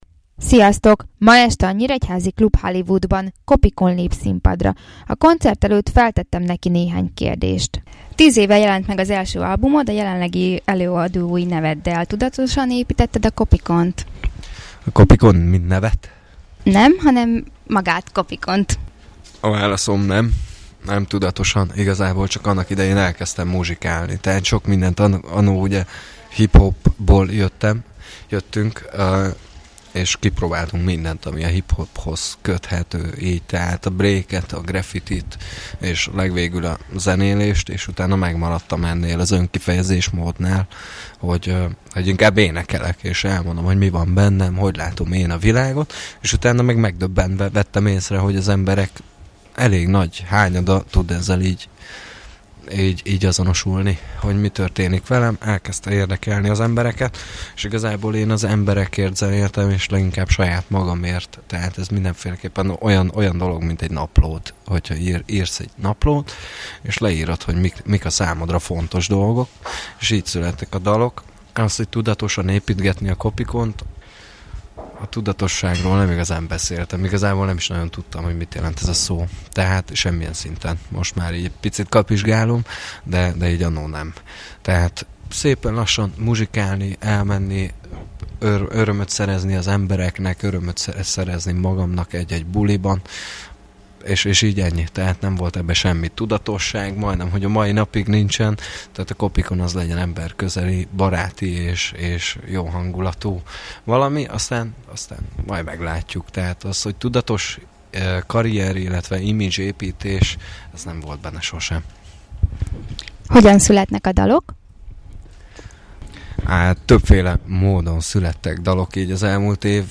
inderju_vagott.mp3